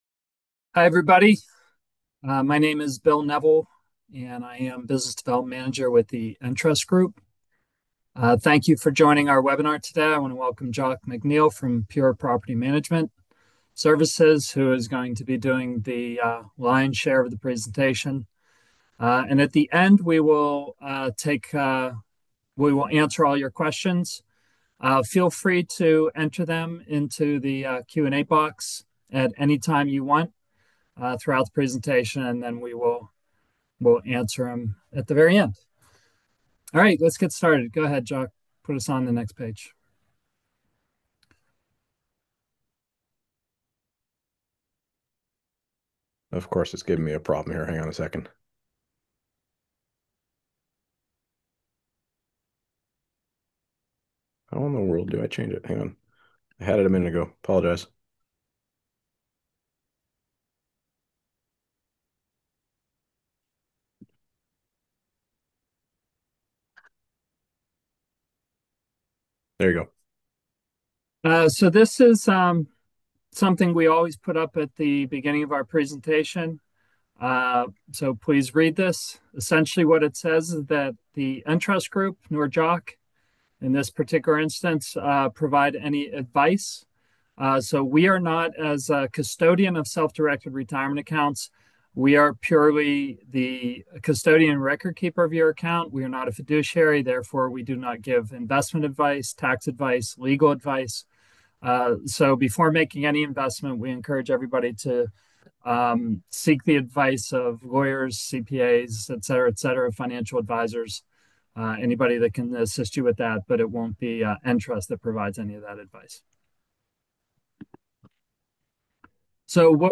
In this replay, you will learn how experienced investors use these partnerships to accelerate real estate investing success and grow smarter. This is an educational webinar — no sales pitch, no pressure.